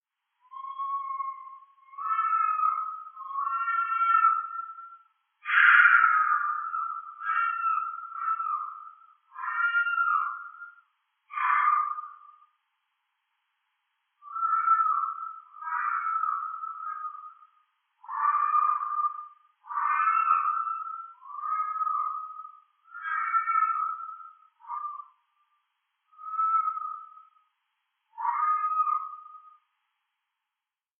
Звуки оленя
Звук оленей, кричащих в темноте